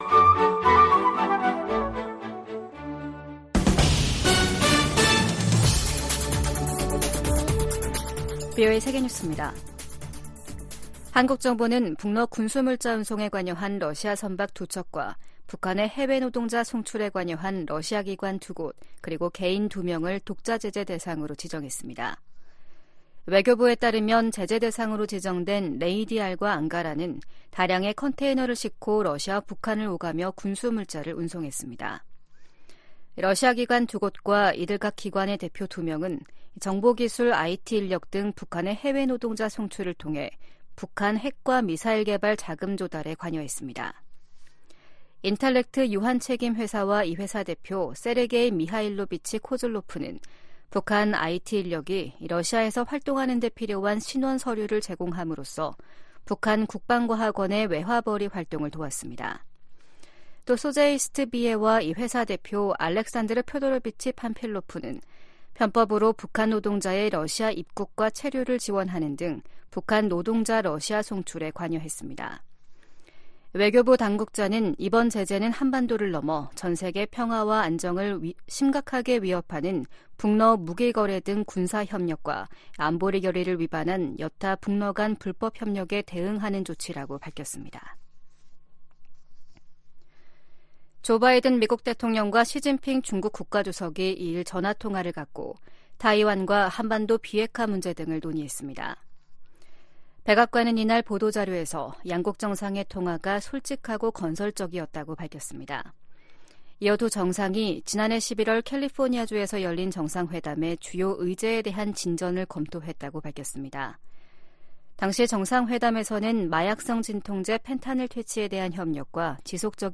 VOA 한국어 아침 뉴스 프로그램 '워싱턴 뉴스 광장' 2024년 4월 3일 방송입니다. 북한이 보름 만에 또 다시 중거리 극초음속 미사일로 추정되는 탄도미사일을 동해상으로 발사했습니다. 미국은 러시아가 북한 무기를 받은 대가로 유엔 대북제재 전문가패널의 임기 연장을 거부했다고 비판했습니다. 주한미군이 중국과 타이완 간 전쟁에 참전할 경우 한국도 관여를 피하기 어려울 것으로 전 주일미군사령관이 전망했습니다.